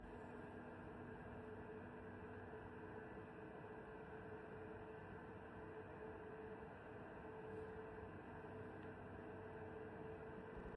Es geht um das Brummende Nebengeräusch.
Anhänge Grafikkarte.m4a Grafikkarte.m4a 172,3 KB